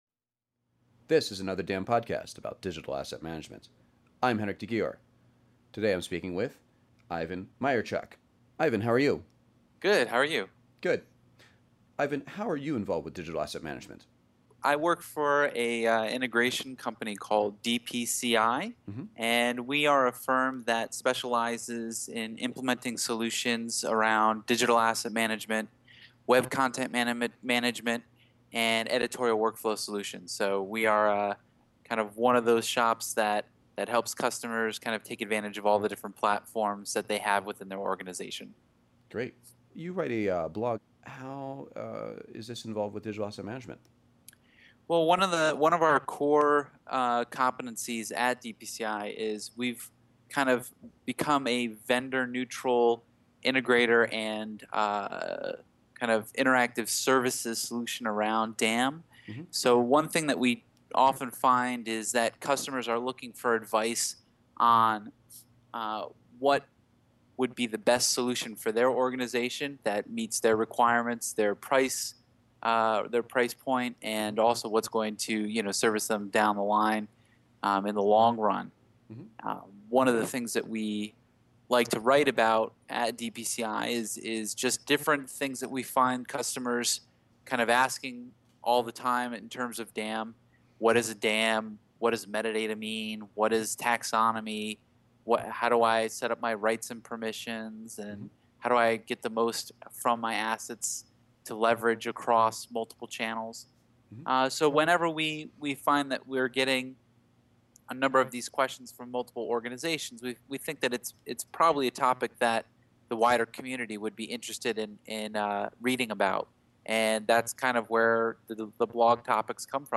Every person interviewed is asked at least three similar questions: